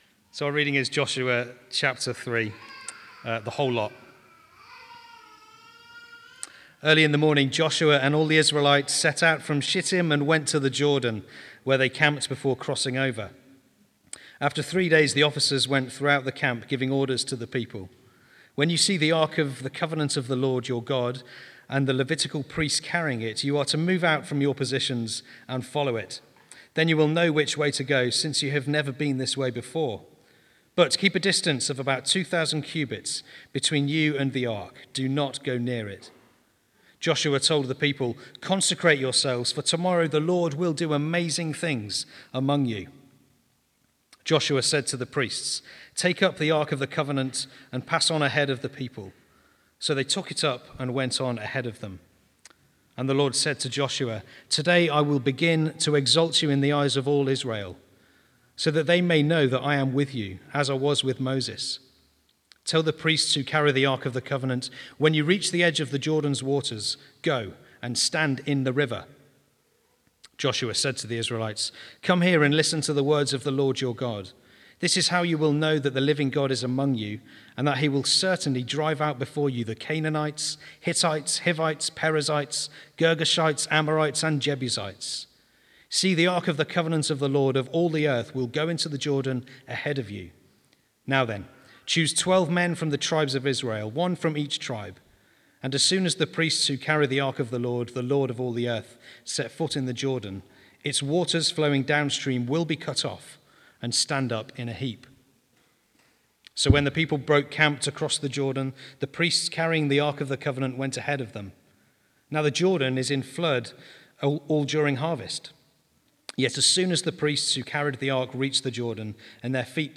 Service Type: Sunday 11:00am